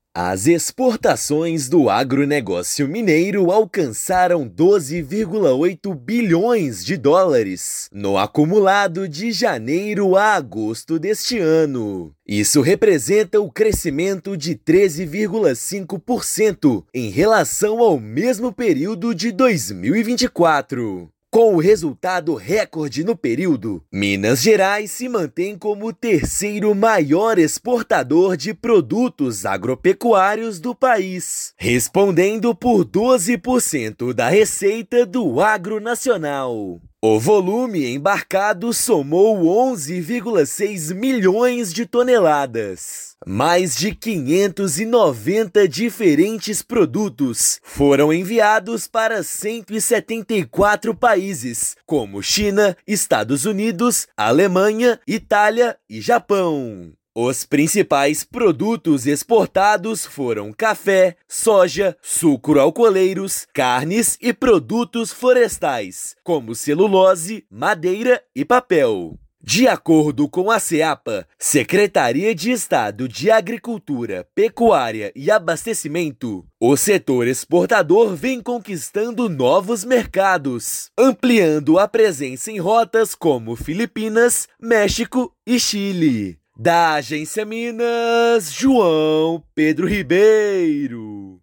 Valor é recorde para o período de janeiro a agosto; mais de 590 diferentes produtos agropecuários mineiros foram enviados para 174 países. Ouça matéria de rádio.